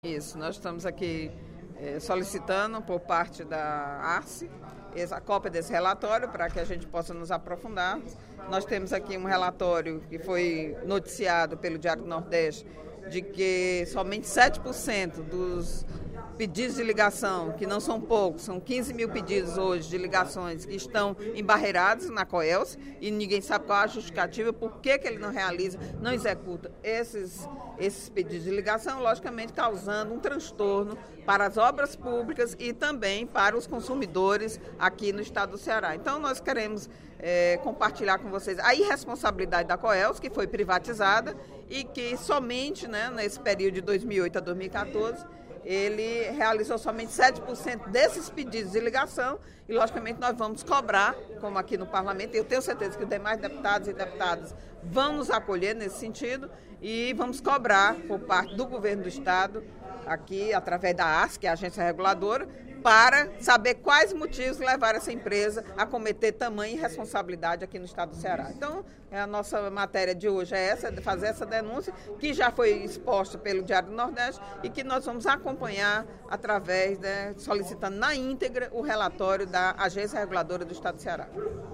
A deputada Eliane Novais (PSB) criticou, durante o primeiro expediente da sessão plenária desta terça-feira (10/06), o serviço de fornecimento de energia elétrica prestado pela Coelce no Estado.